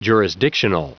Prononciation du mot jurisdictional en anglais (fichier audio)
Prononciation du mot : jurisdictional